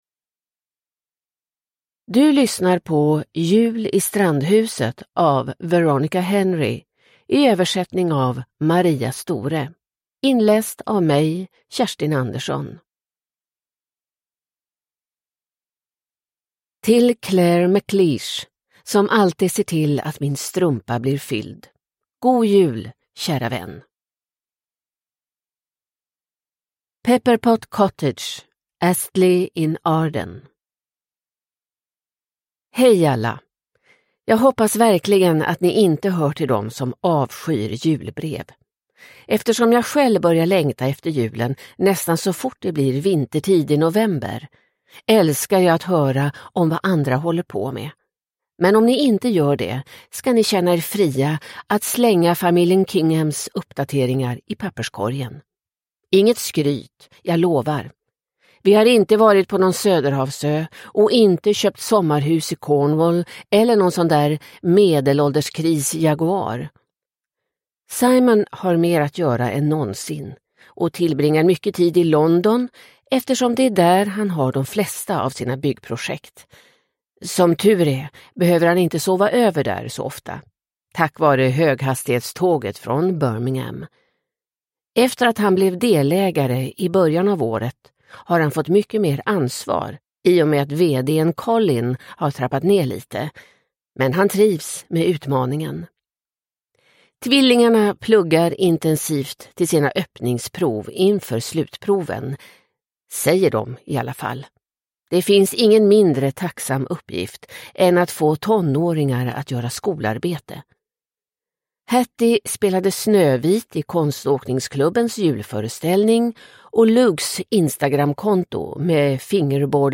Jul i strandhuset – Ljudbok – Laddas ner